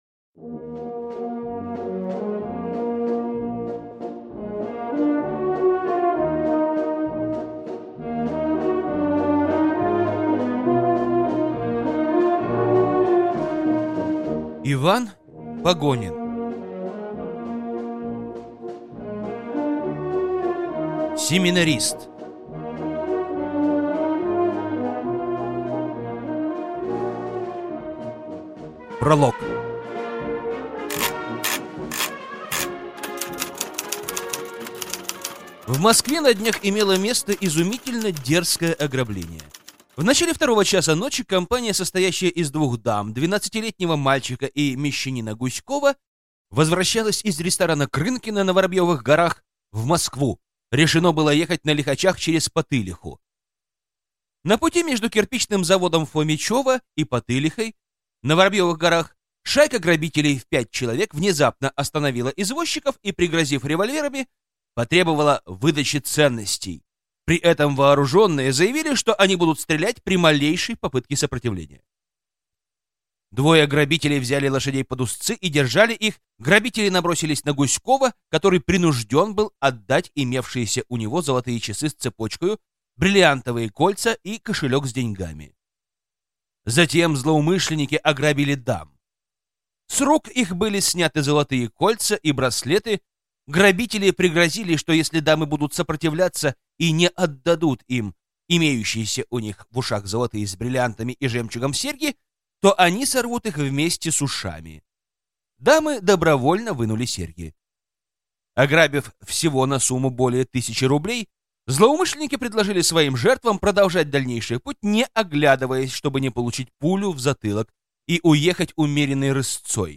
Аудиокнига Тайна Святой Эльжбеты | Библиотека аудиокниг
Прослушать и бесплатно скачать фрагмент аудиокниги